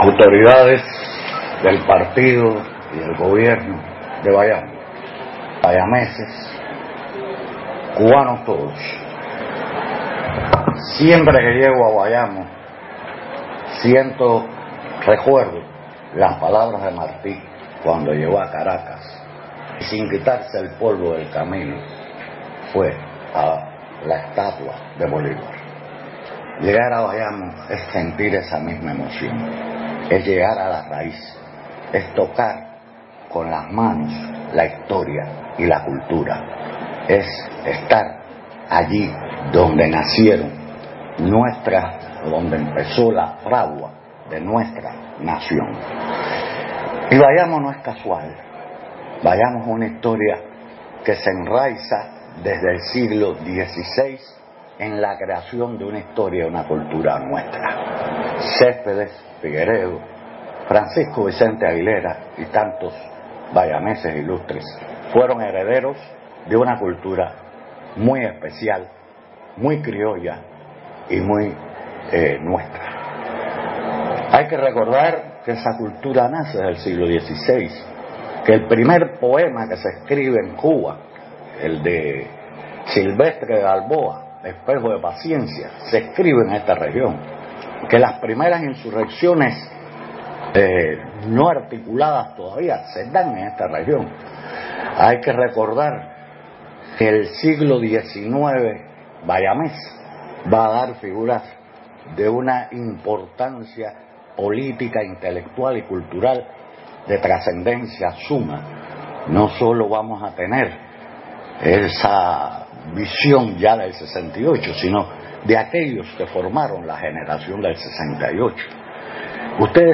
Doctor-en-ciencias-Eduardo-Torres-Cuevas-a-cargo-de-las-palabras-inaugurales-de-la-Cuban--a1.mp3